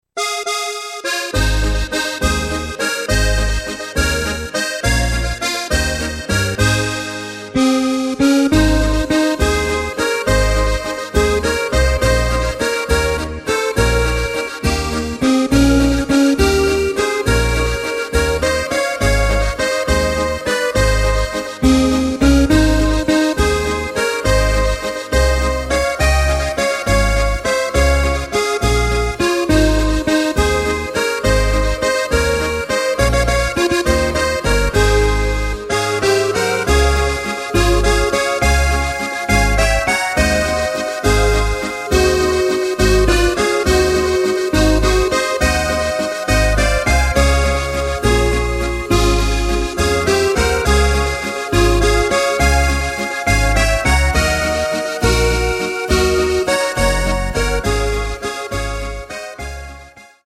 Takt:          3/4
Tempo:         206.00
Tonart:            Ab
Walzer Schweiz aus dem Jahr 2018!